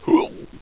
gulp.wav